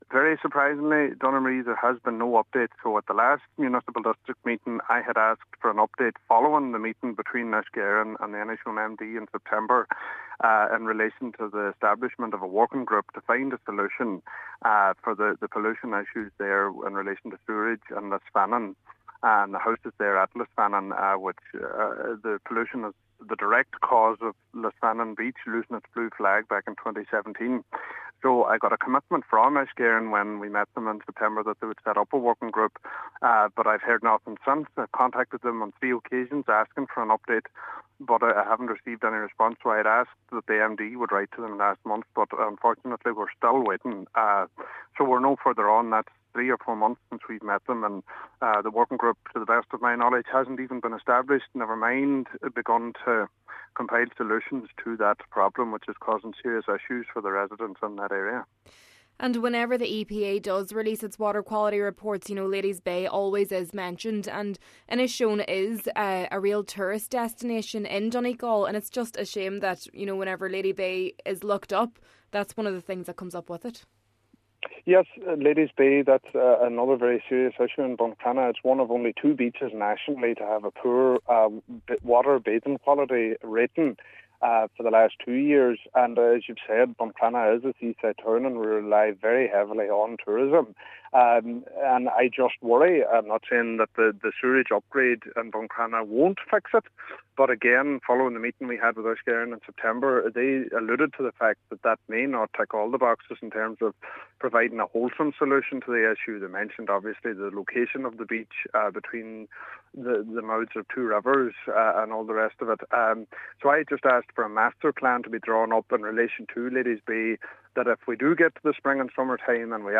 He adds that, as a tourism town, the pollution is harming local businesses: